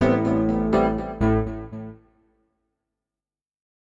SYNTH016_VOCAL_125_A_SC3(L).wav
1 channel